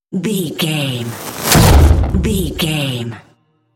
Dramatic whoosh to hit trailer
Sound Effects
dark
intense
tension
woosh to hit